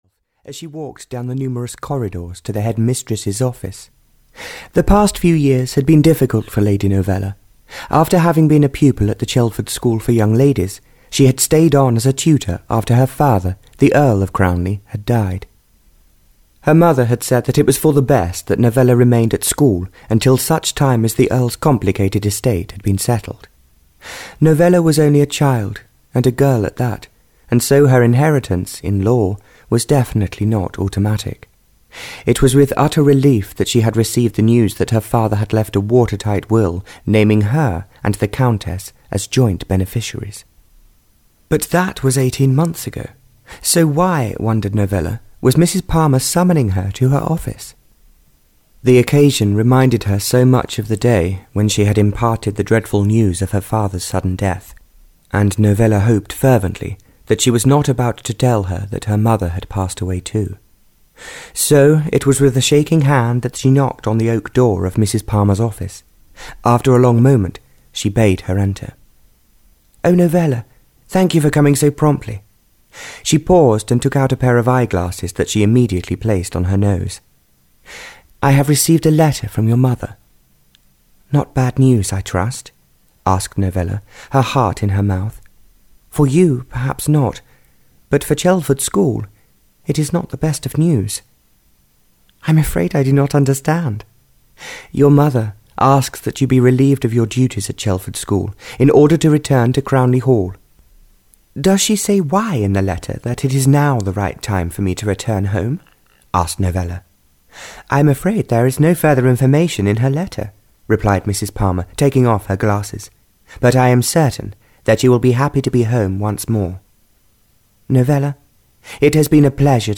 Audio knihaLove is the Reason for Living (Barbara Cartland’s Pink Collection 25) (EN)
Ukázka z knihy